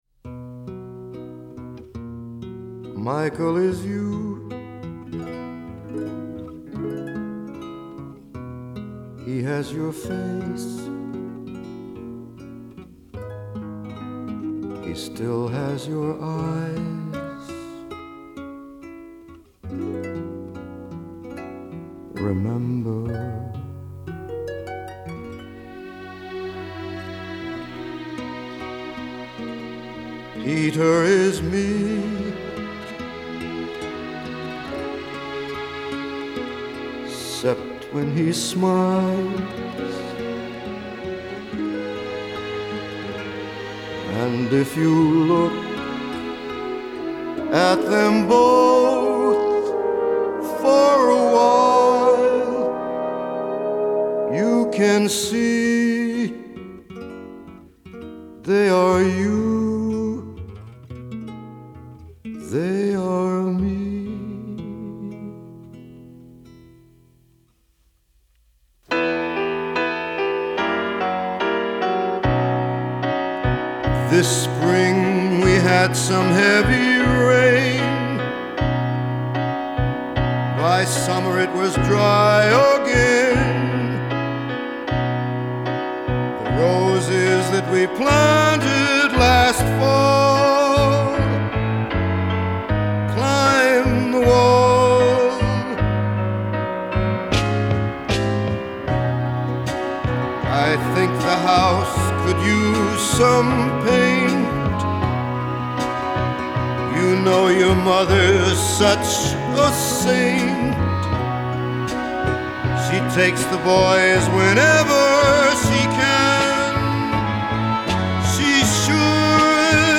Genre : Lounge, Électronique